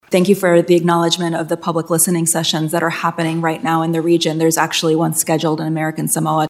NOAA Deputy Administrator Jainey Bavishi responded at the Natural Resources Committee hearing…